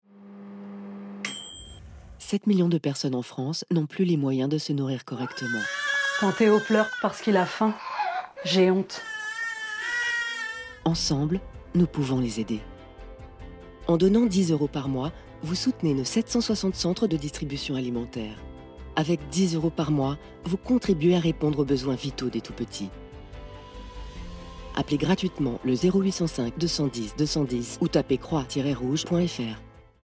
Voix Off Féminine
Type de voix : Sérieuse, Classe